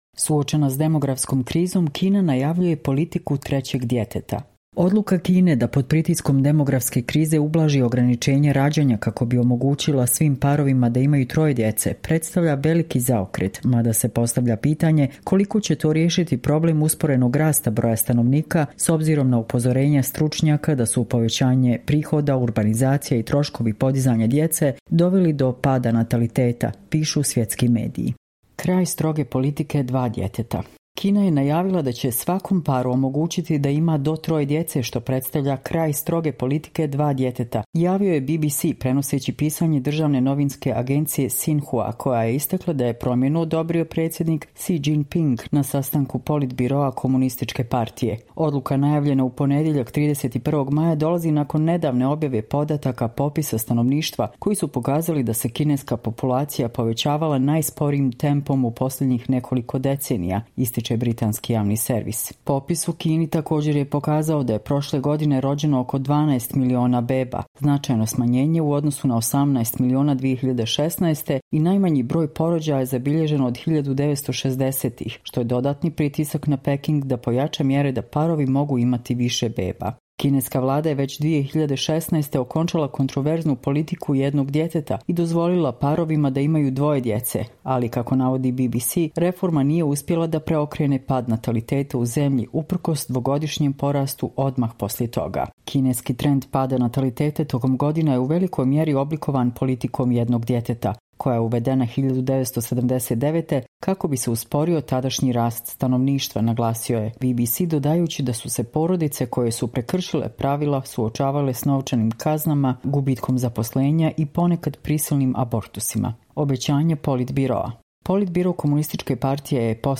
Čitamo vam: Suočena s demografskom krizom Kina najavljuje politiku 'trećeg djeteta'